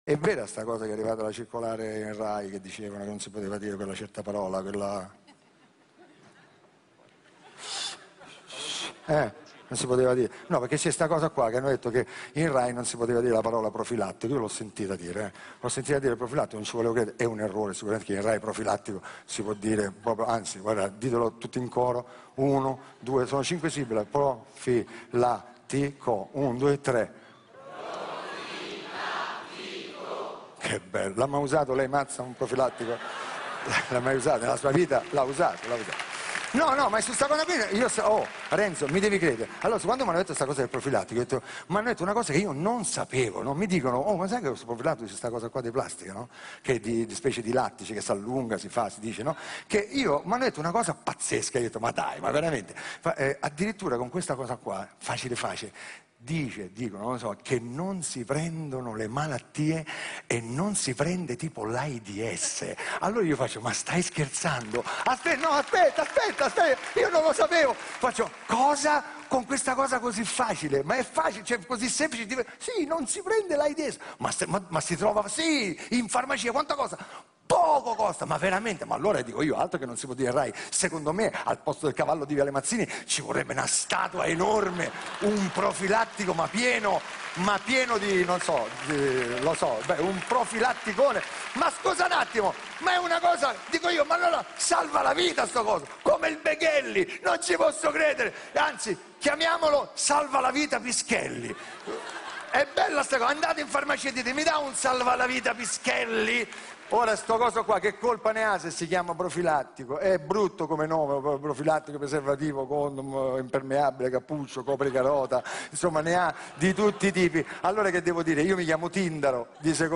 Show di Fiorello ieri serasu Rai Uno.